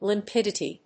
音節lim・pid・i・ty 発音記号・読み方
/lɪmpídəṭi(米国英語)/